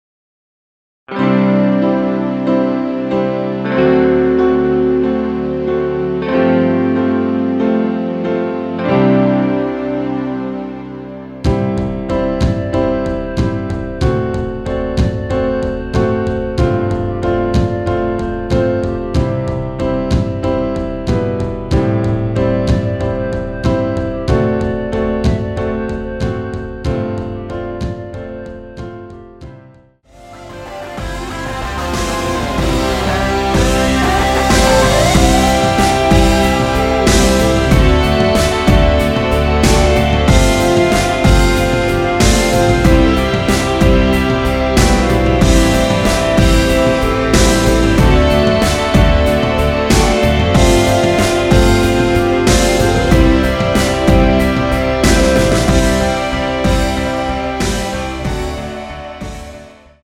원키에서(-1)내린 멜로디 포함된 MR입니다.
앞부분30초, 뒷부분30초씩 편집해서 올려 드리고 있습니다.
위처럼 미리듣기를 만들어서 그렇습니다.